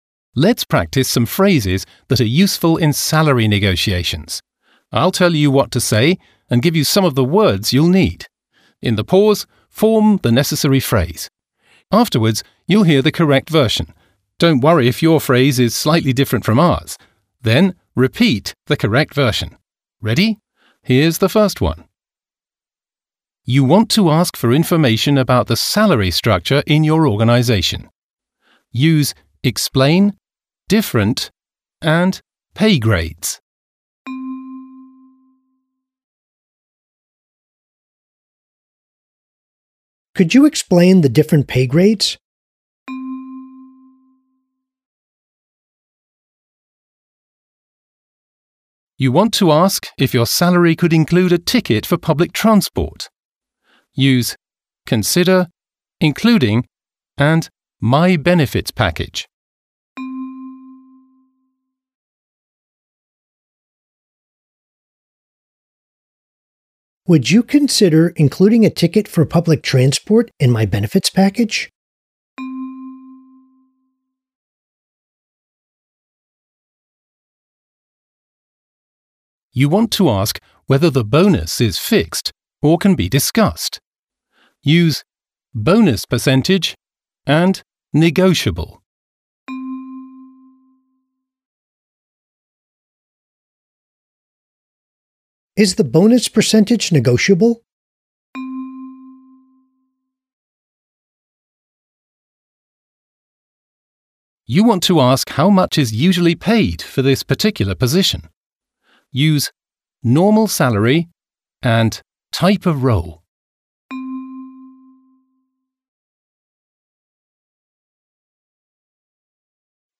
Audio-Übung
Audio-Trainer